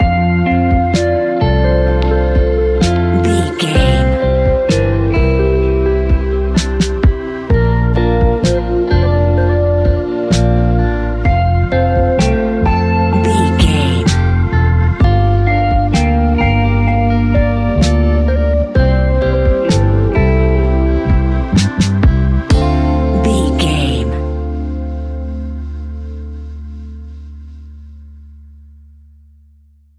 Ionian/Major
D
chilled
laid back
Lounge
sparse
chilled electronica
ambient
atmospheric
morphing